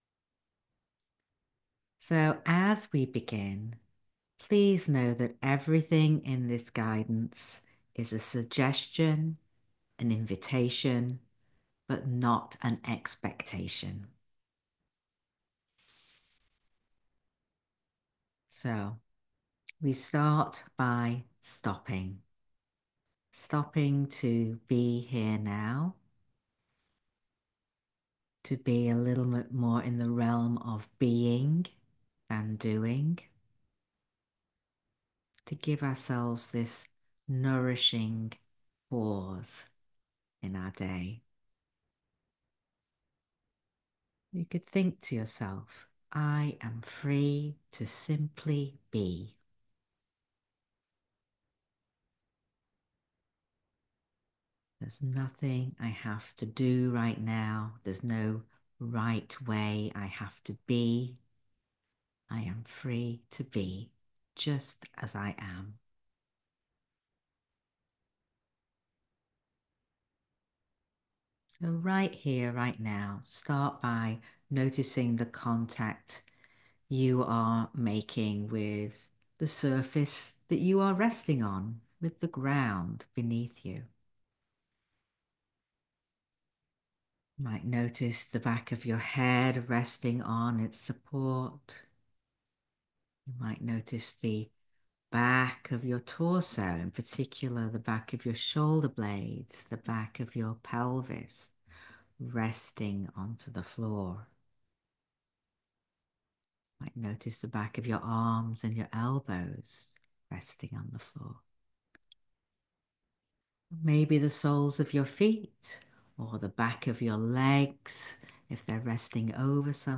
talk given during a live session on January 31, 2025. Listen to it during Constructive Rest to guide your thinking and awareness.